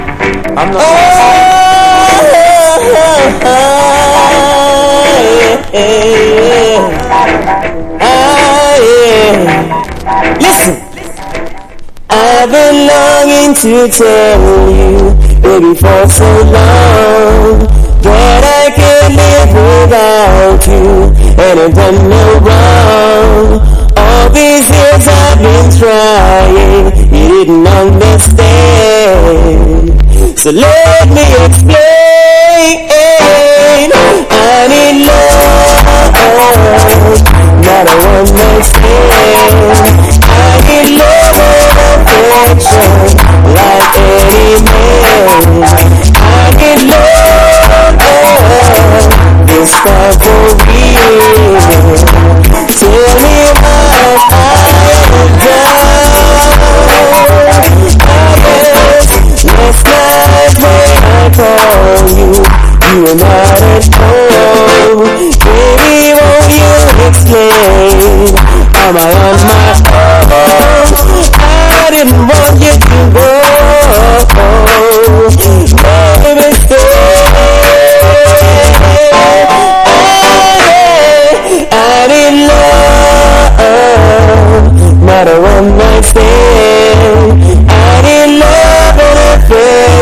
• REGGAE-SKA
LOVERS# DANCE HALL